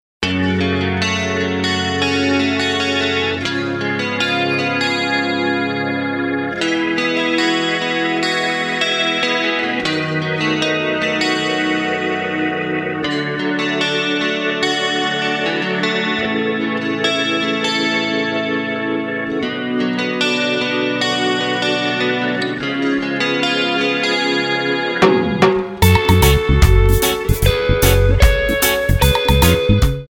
Tonart:F#m Multifile (kein Sofortdownload.
Die besten Playbacks Instrumentals und Karaoke Versionen .